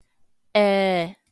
Long and short vowel sounds
Short E (audio/mpeg)